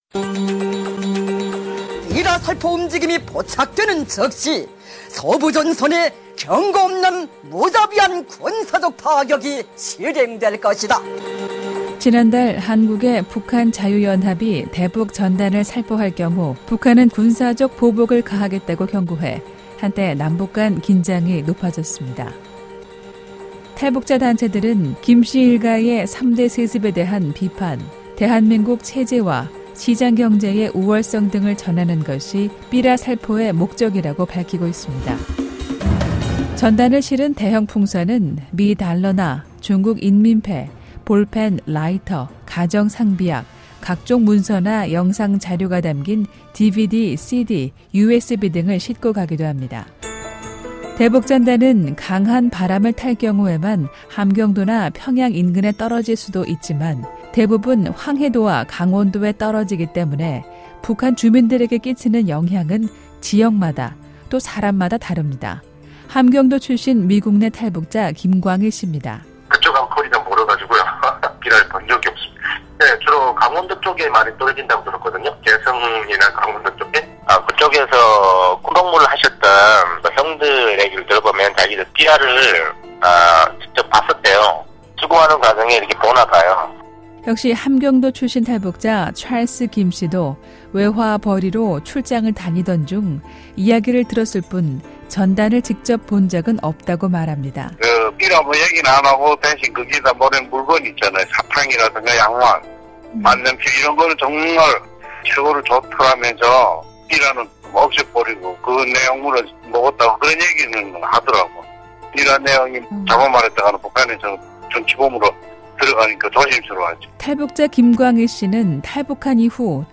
매주 화요일 화제성 뉴스를 전해드리는 ‘뉴스 풍경’입니다. 최근 남북한은 대북 전단 살포를 놓고 갈등을 빚었는데요. 대북 전단이 북한 주민들에게 어떤 의미인지 탈북자들에게 물어봤습니다.